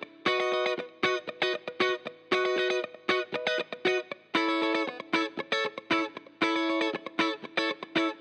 04 Guitar PT3.wav